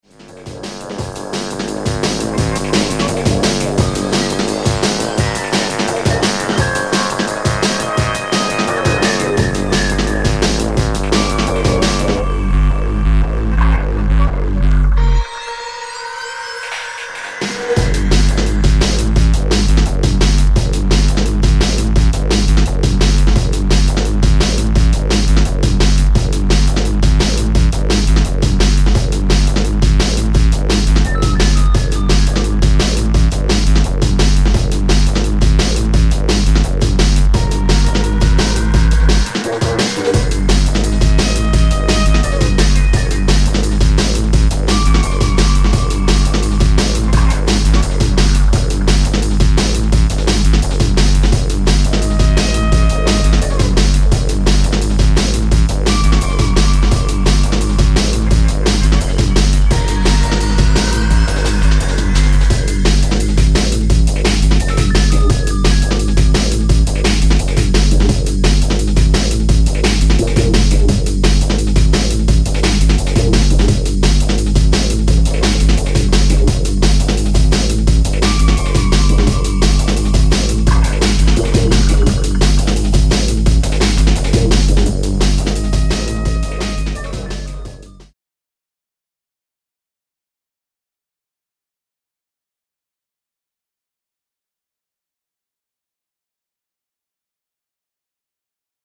＊視聴音源は実物のレコードから録音してます。